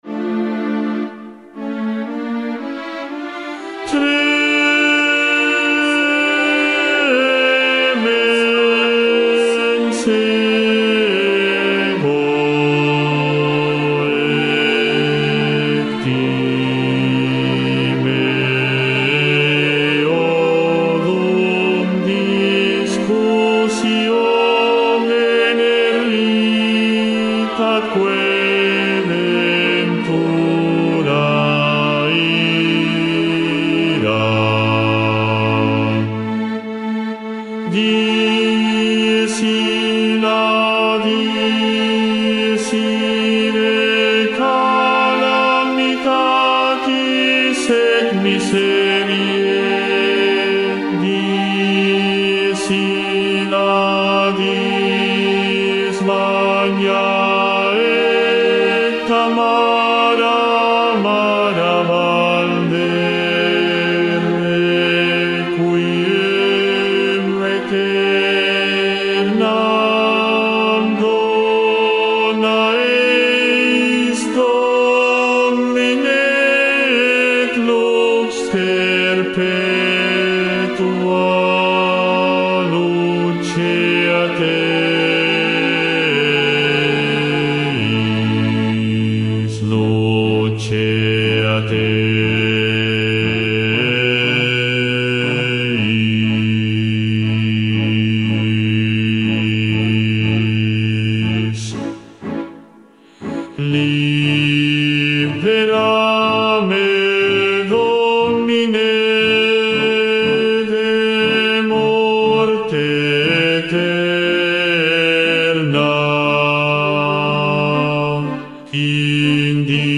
Bajo II
6.-Libera-me-BAJO-II-VOZ.mp3